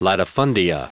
Prononciation du mot latifundia en anglais (fichier audio)
Prononciation du mot : latifundia